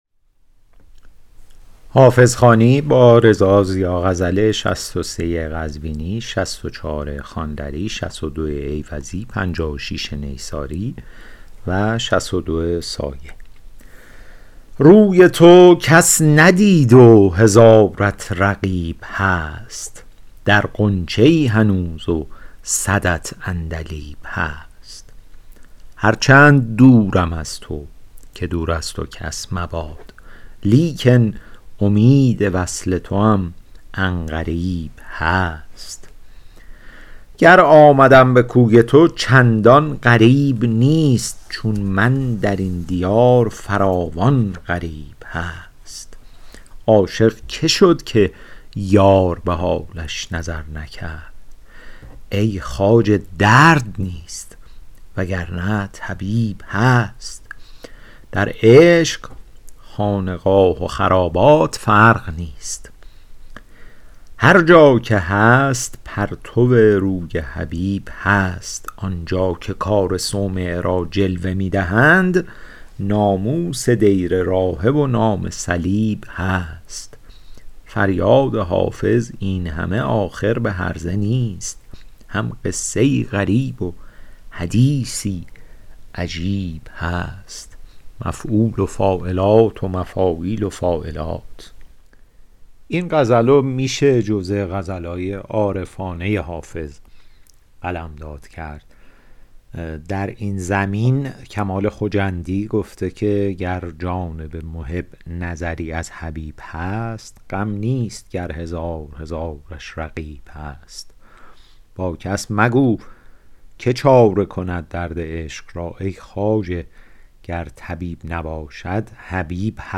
حافظ غزلیات شرح صوتی